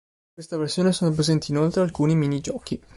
pre‧sèn‧ti
/preˈzɛn.ti/